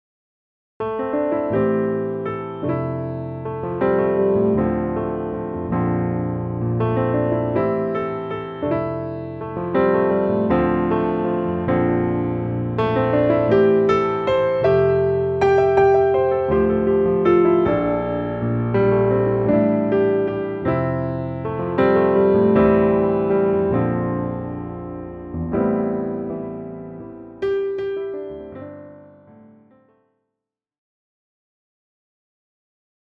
Yesterday was moodily wet and dark in Seattle. I hit record and this moody… cheese emerged.